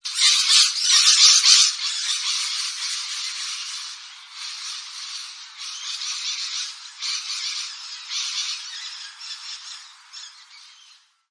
Ringtone Parrots 1
Category Sound Effects